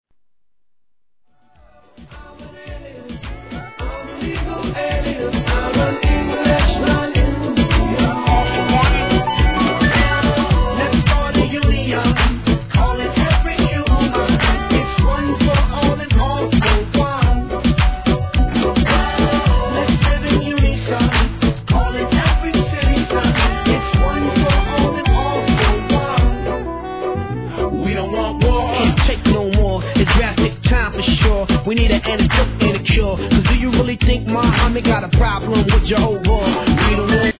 HIP HOP/R&B
フロアの反応が楽しみな驚愕REMIX!!!
BPM 107